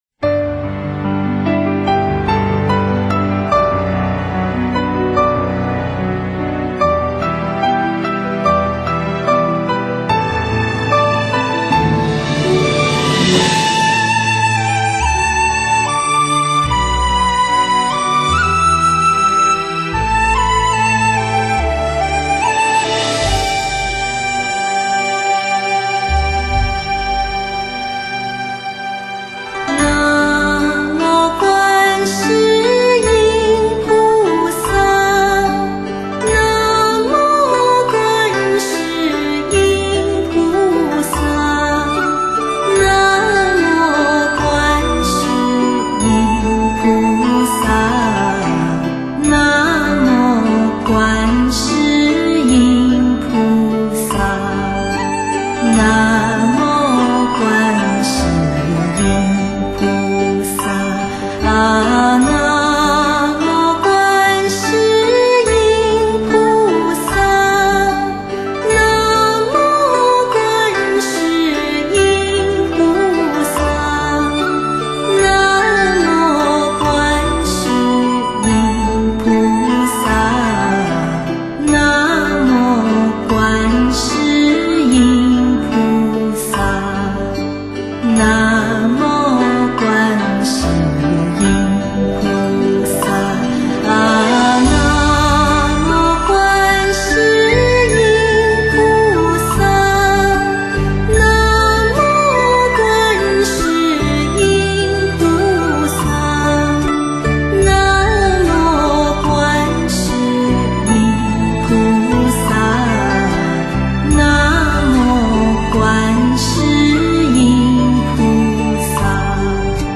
[14/1/2010]甜润优美的新唱诵：南无观世音菩萨圣号